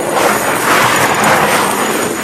item_slide_loop_01.ogg